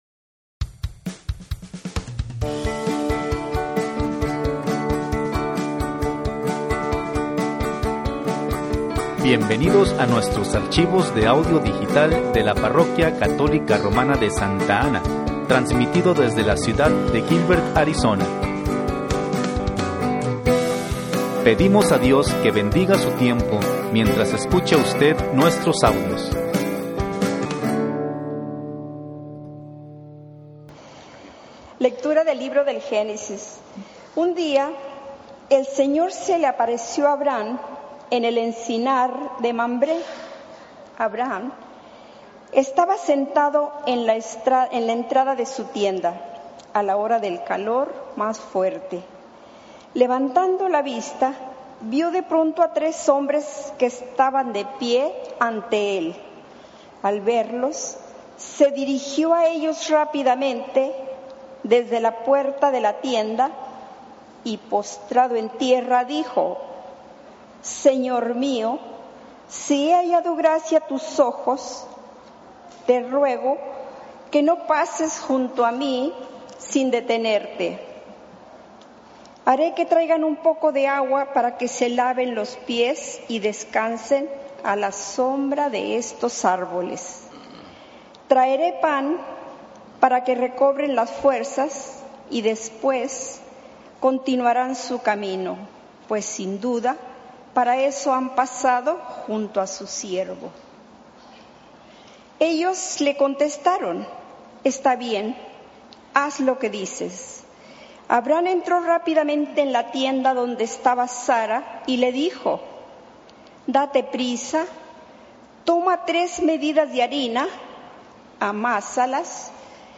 XVI Domingo de Tiempo Ordinario (Lecturas)
Evangelio, Lecturas, Tiempo Ordinario